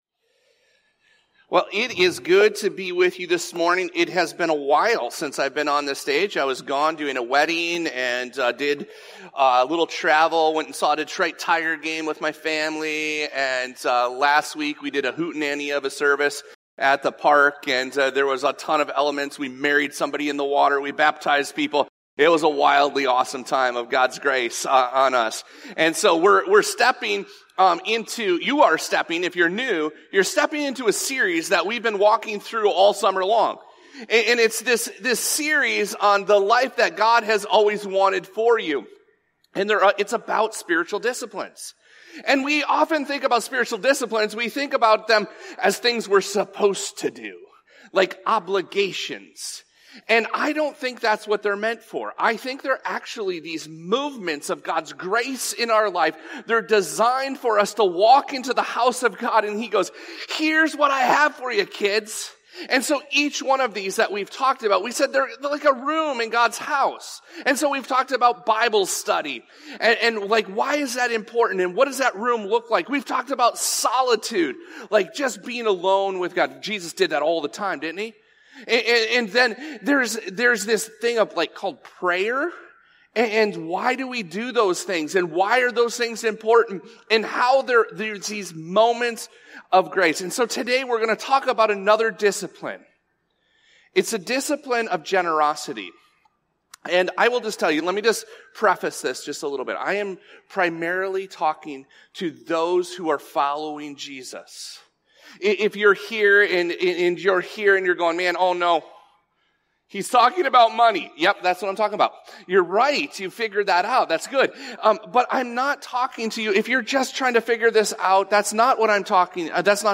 This podcast episode is a Sunday message from Evangel Community Church, Houghton, Michigan, August 17, 2025.